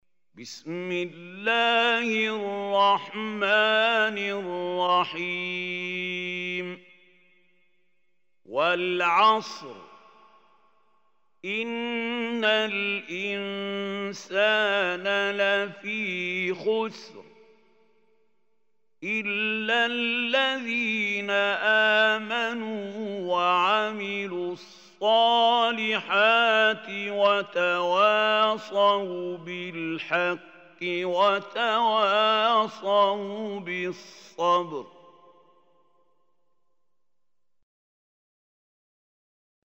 Surah Al Asr Recitation by Mahmoud Khalil Hussary
Surah Al Asr is 103 surah of Holy Quran. Listen or play online mp3 tilawat / recitation in Arabic in the beautiful voice of Sheikh Mahmoud Khalil Al Hussary.